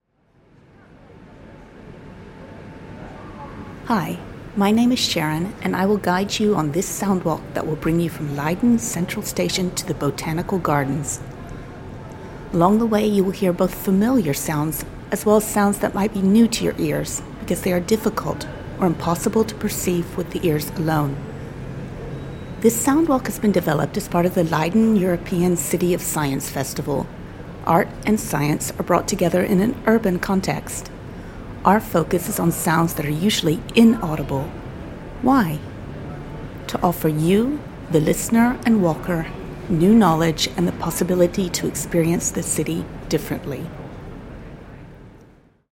Leiden (Un)heard makes it possible to experience the inner city of Leiden in an alternative way by listening to its sounds that cannot be heard normally.
Audiowalk, English.
“Leiden (Un)Heard” is a soundwalk that invites you to listen to Leiden through different “ears,” using recording techniques that offer new perspectives on known places or to listen in on spaces that usually go unheard.
This soundwalk will give your ears a listen into Leiden’s sonic secrets.